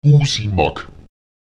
Lautsprecher gúsimak [Èguùsimak] das Medikament (etwas, das heilt)